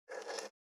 554魚切る,肉切りナイフ,まな板の上,包丁,ナイフ,調理音,料理,
効果音